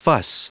Prononciation du mot fuss en anglais (fichier audio)
Prononciation du mot : fuss